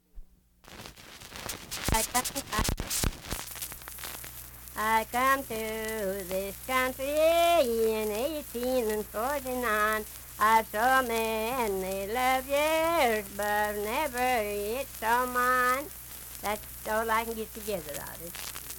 Unaccompanied vocal music
Voice (sung)
Logan County (W. Va.), Lundale (W. Va.)